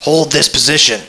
voice_hold.wav